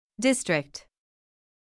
※当メディアは、別途記載のない限りアメリカ英語の発音を基本としています。
【中上級 /s/の発音】
/ˈdɪstrɪkt/